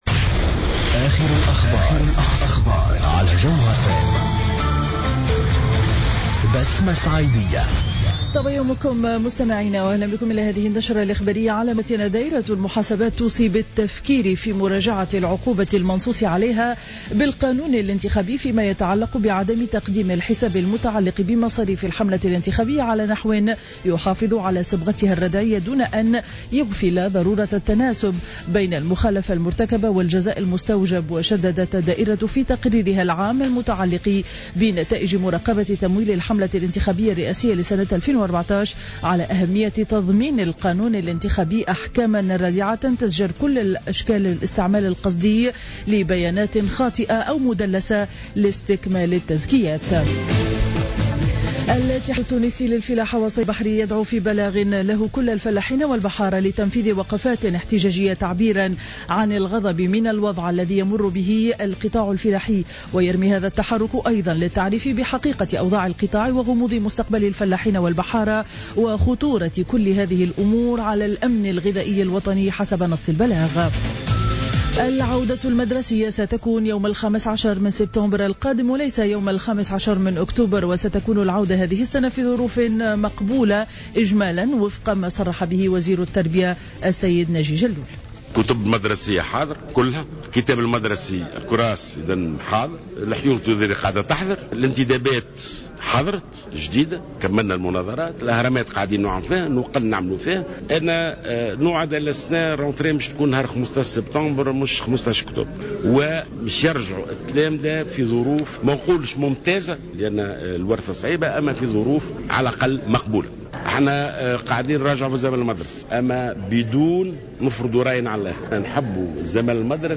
نشرة أخبار السابعة صباحا ليوم الجمعة 21 أوت 2015